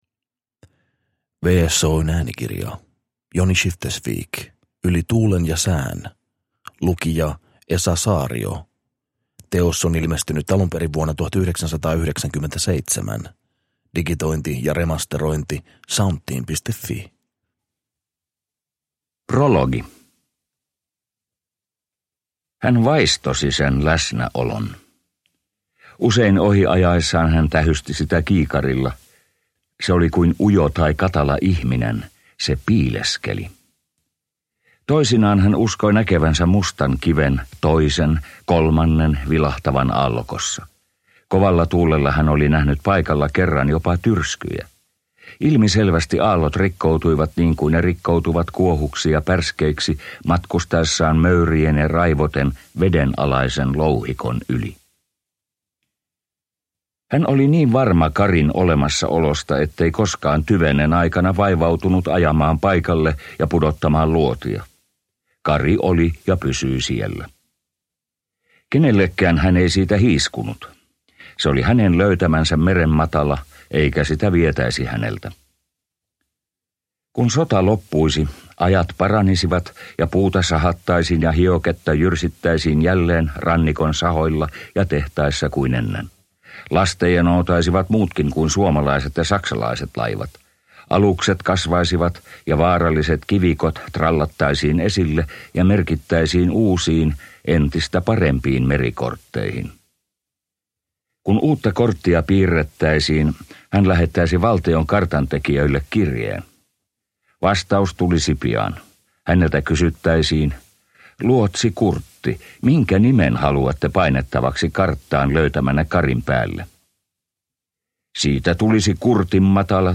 Yli tuulen ja sään – Ljudbok